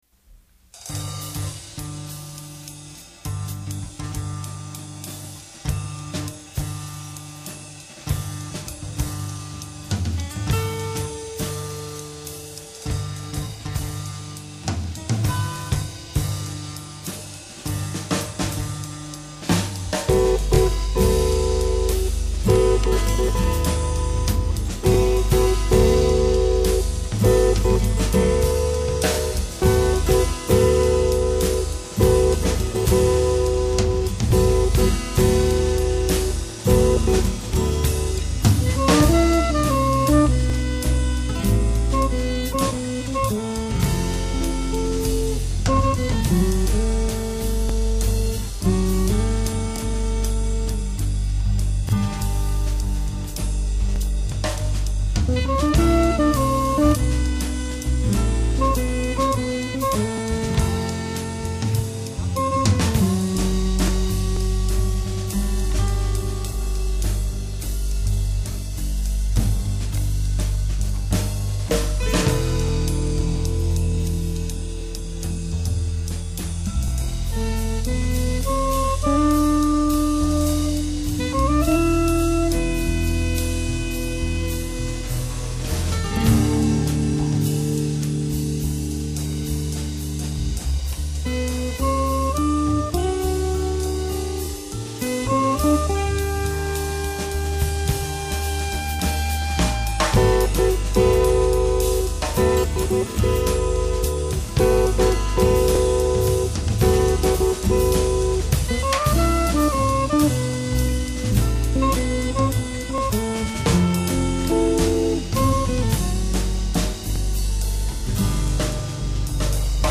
acoustic jazz quartet